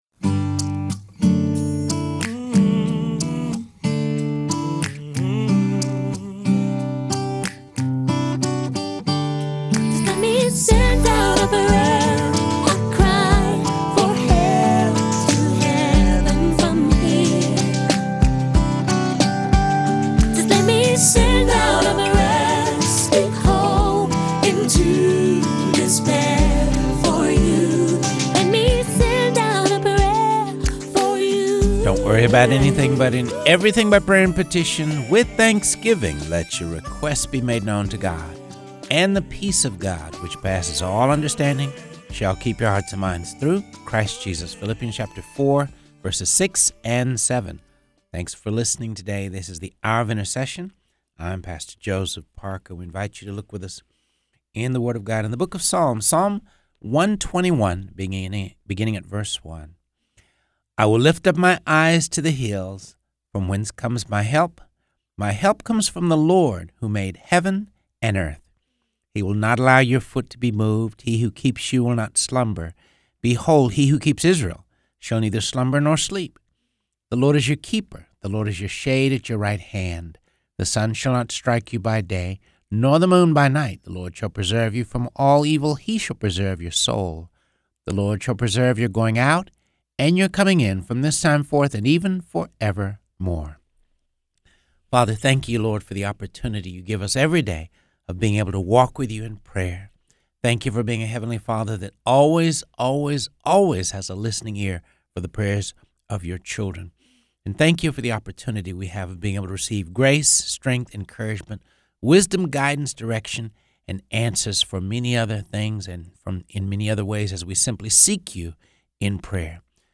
Reading through the Word of God | Episode 51